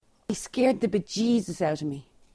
Examples of English from the Irish Republic
//hi skeːrd ðə bəʹdʒiːzəs ɑʊt əv miː//
Notice the pronunciation of scared. In RP it would be a diphthong /eə/. In Irish English there are no centring diphthongs as the "r" is pronounced. So we have /eːr/.